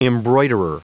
Prononciation du mot embroiderer en anglais (fichier audio)
Prononciation du mot : embroiderer